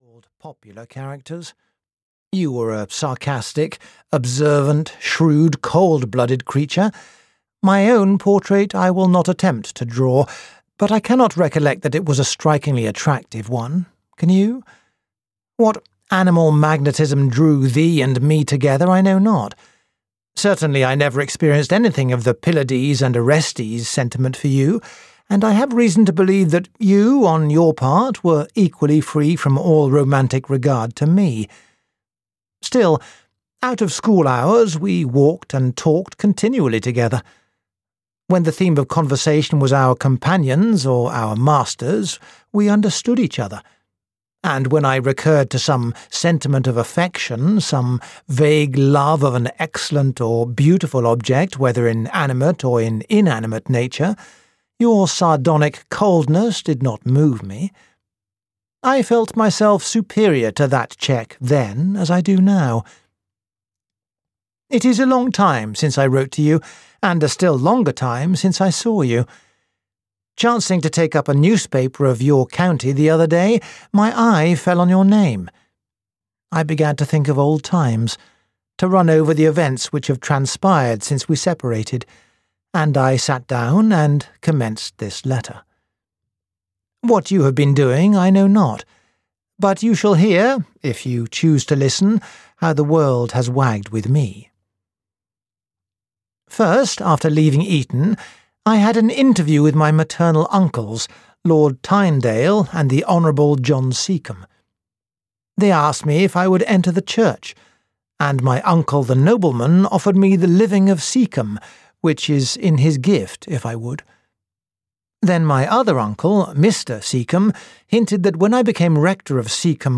The Professor (EN) audiokniha
Ukázka z knihy